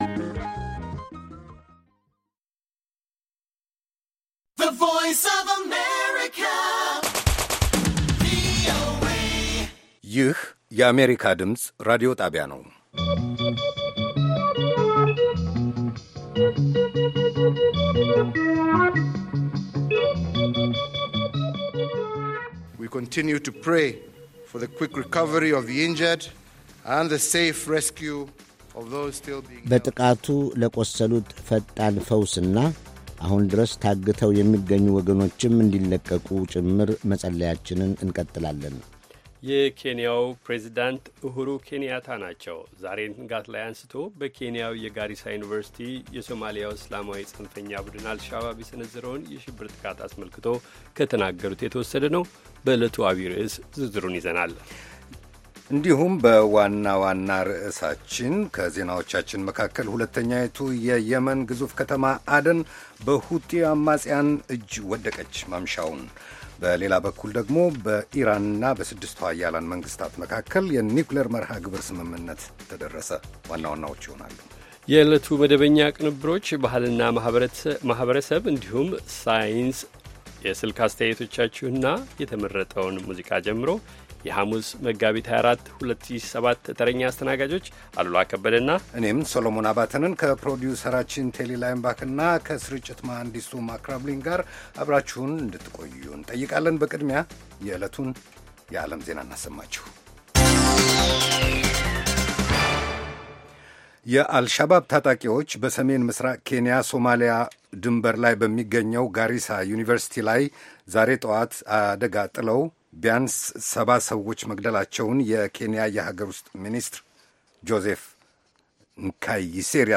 ቪኦኤ በየዕለቱ ከምሽቱ 3 ሰዓት በኢትዮጵያ ኣቆጣጠር ጀምሮ በአማርኛ፣ በአጭር ሞገድ 22፣ 25 እና 31 ሜትር ባንድ የ60 ደቂቃ ሥርጭቱ ዜና፣ አበይት ዜናዎች ትንታኔና ሌሎችም ወቅታዊ መረጃዎችን የያዙ ፕሮግራሞች ያስተላልፋል። ሐሙስ፡- ባሕልና ማኅበረሰብ፣ ሕይወት በቀበሌ፣ የተፈጥሮ አካባቢ፣ ሣይንስና ሕይወት